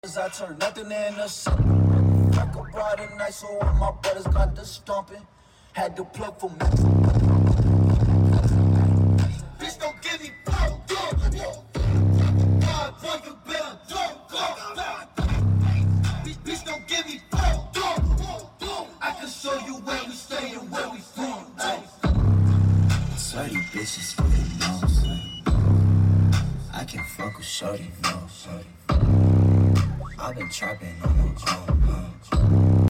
Clipping like crazy but electrical